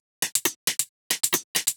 Index of /musicradar/ultimate-hihat-samples/135bpm
UHH_ElectroHatB_135-01.wav